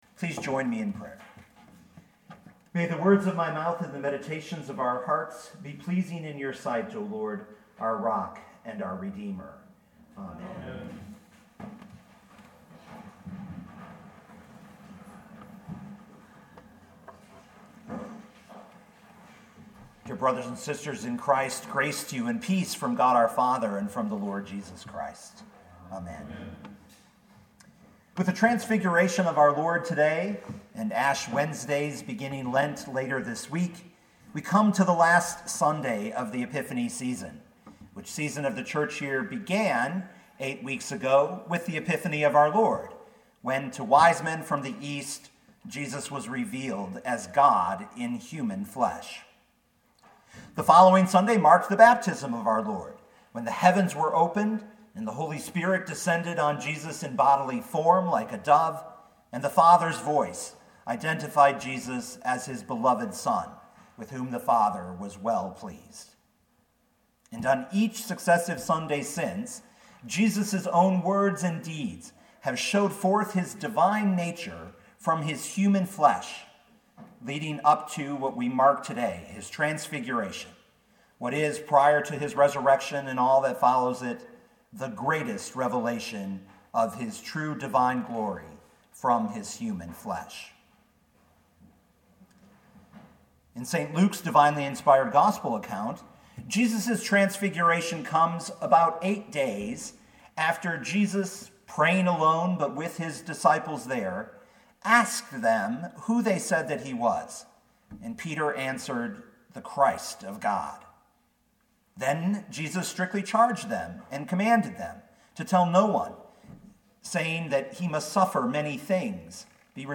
2019 Luke 9:28-36 Listen to the sermon with the player below, or, download the audio.